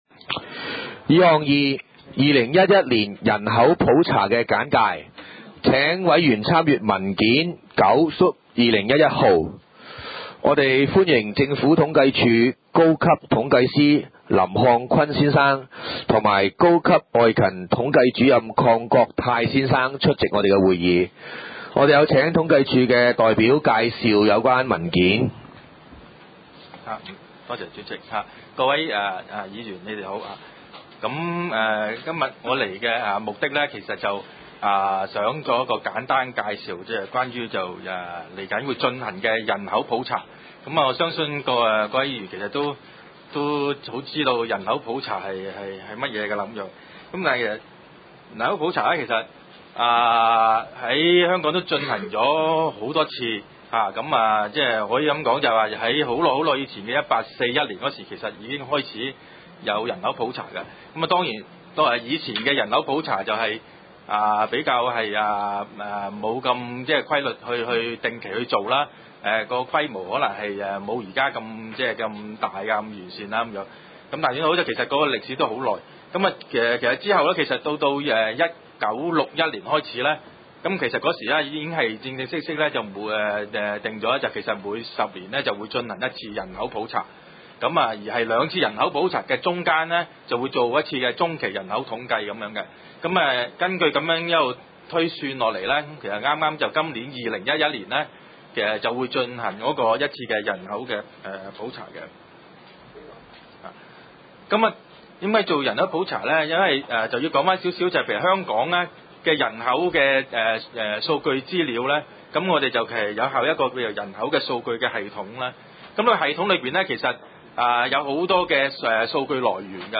第三屆觀塘區議會屬下 房屋事務委員會 第二十三次會議記錄
九龍觀塘同仁街 6 號觀塘政府合署 3 樓觀塘民政事務處會議室